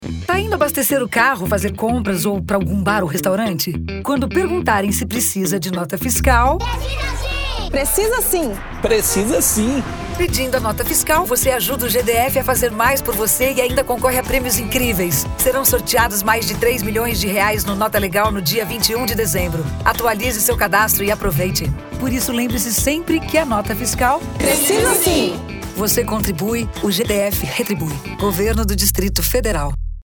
SPOT_IMPOSTOS_GDF_PRECISA-_SIM_13.mp3